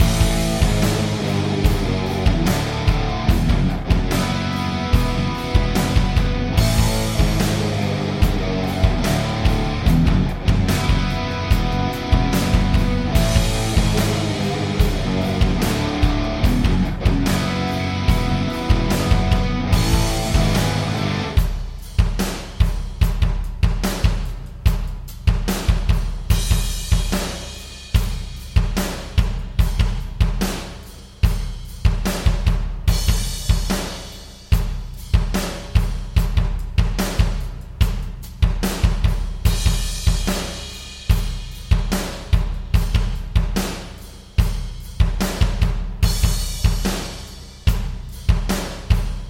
My first experimentation with the DMoll and the TS trying a few different things.